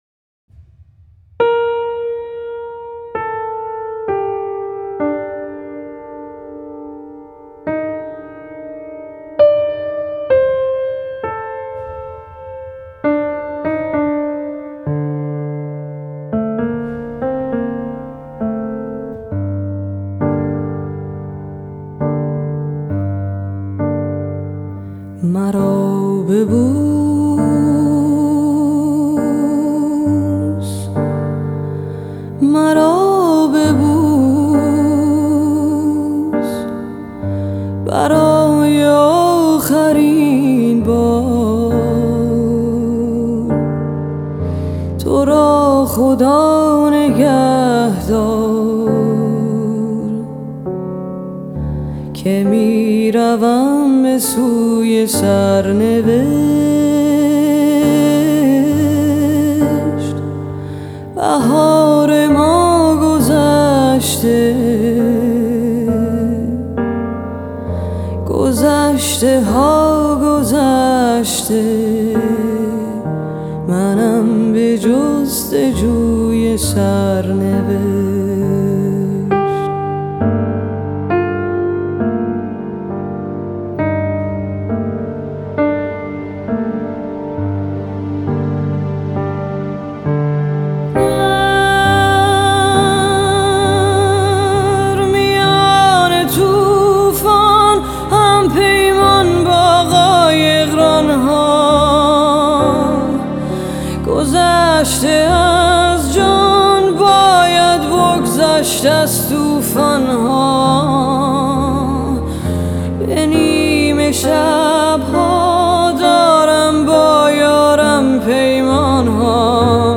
کاور